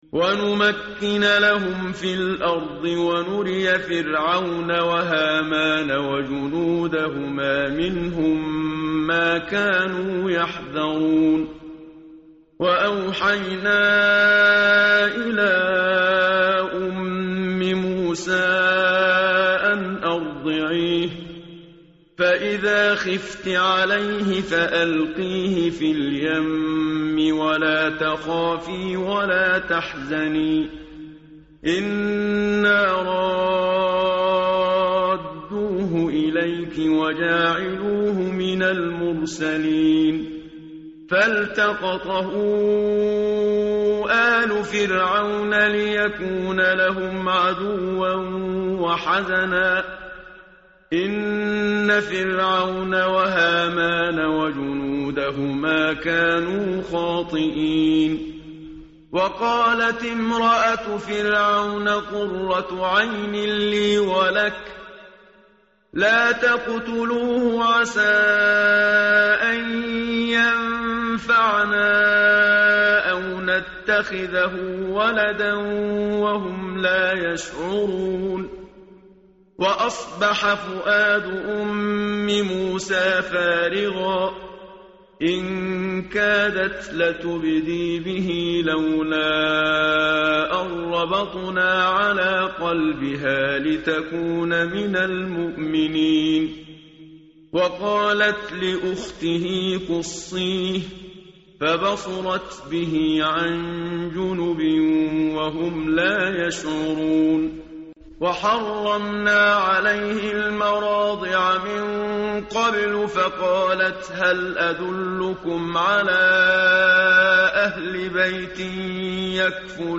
tartil_menshavi_page_386.mp3